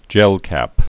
(jĕlkăp)